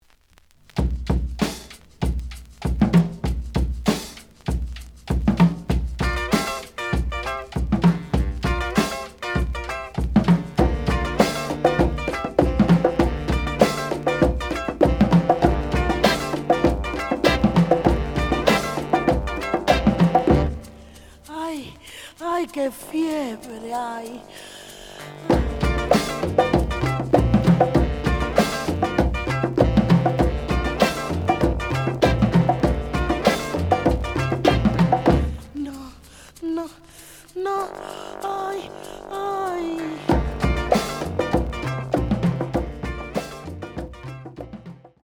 The audio sample is recorded from the actual item.
●Genre: Funk, 70's Funk
Slight click noise on middle of both sides due to a bubble.